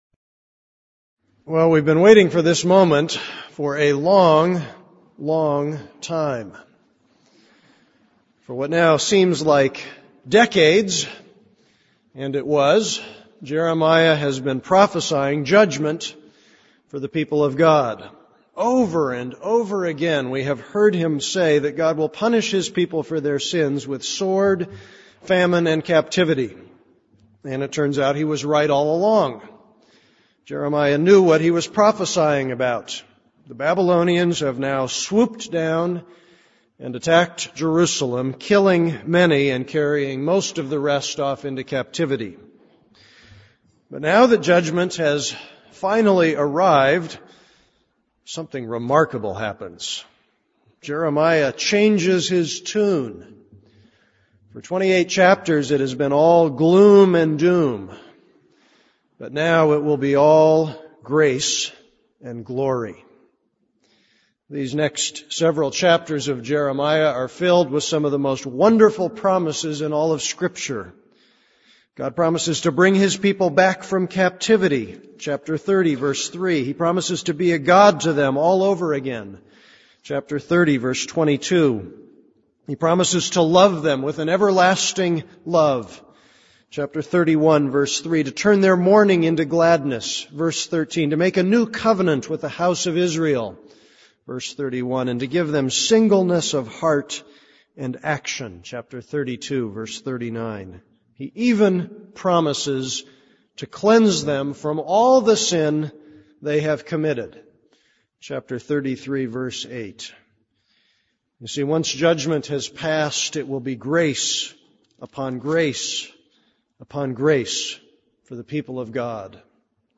This is a sermon on Jeremiah 29:1-9; 24.